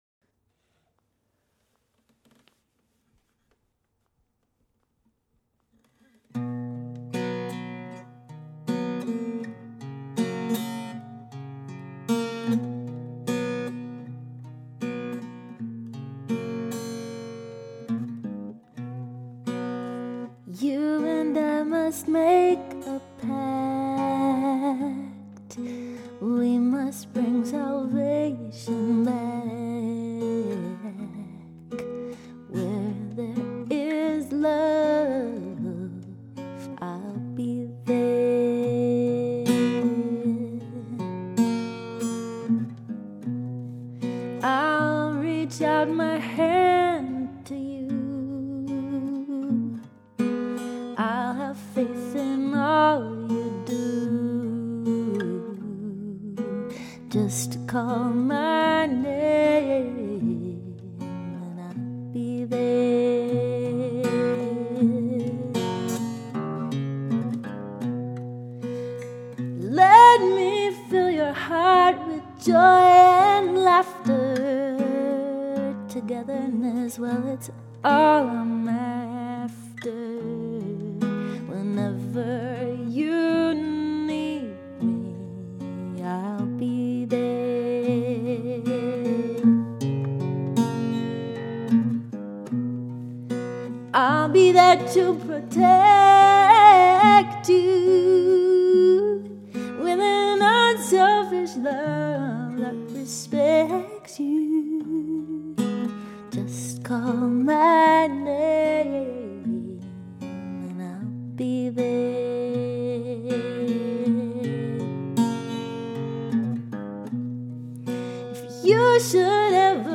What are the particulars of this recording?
just ten minutes ago on my home computer.